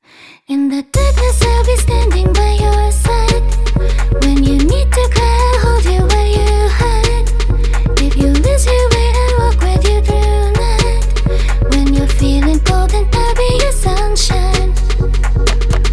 Iの方は3連続で4-1のモーションを繰り返すことになって、当然のことながら自然にこの場所にフィットしています。